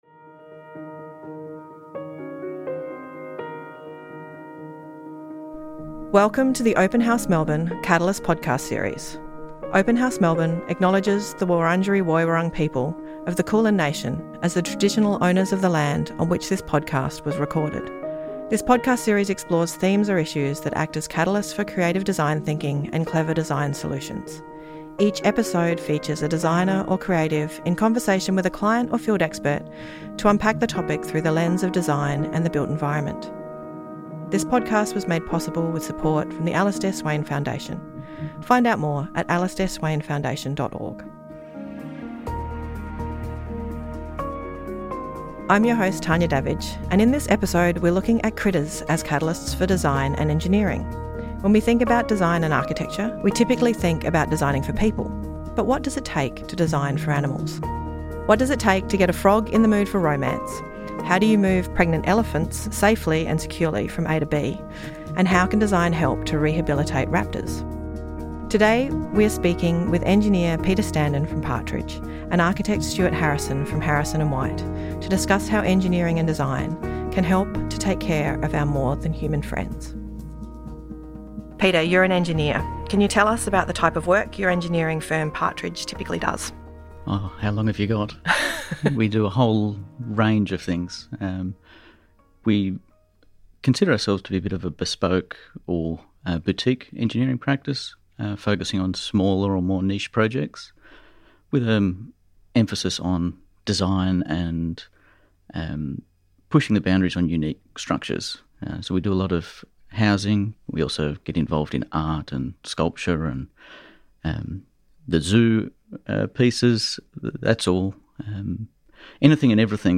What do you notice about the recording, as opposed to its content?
The Catalyst podcast series was recorded at The Push , Collingwood Yards.